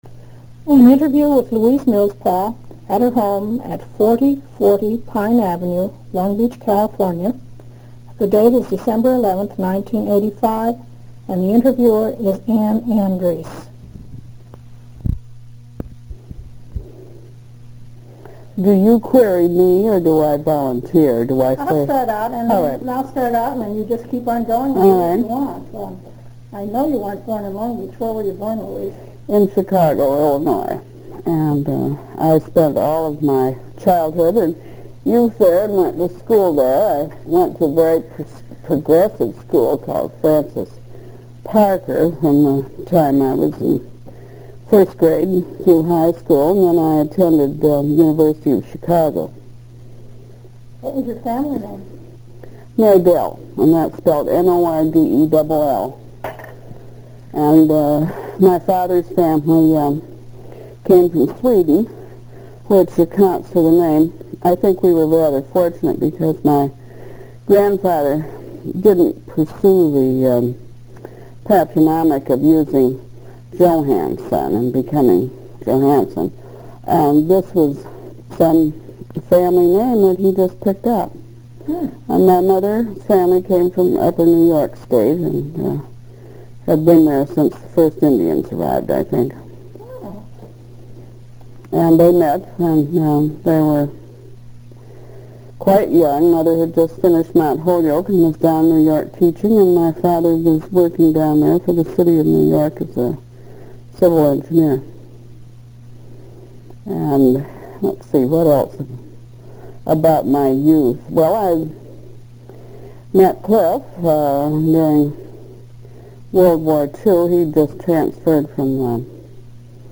INTERVIEW DESCRIPTION
The audio quality of this interview is fair.